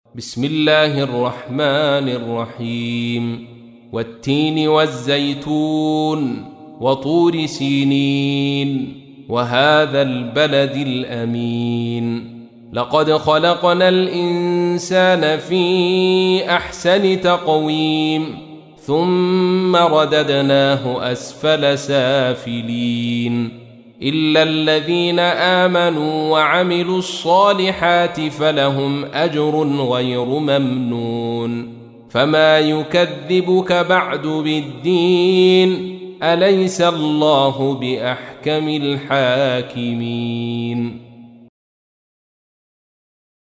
تحميل : 95. سورة التين / القارئ عبد الرشيد صوفي / القرآن الكريم / موقع يا حسين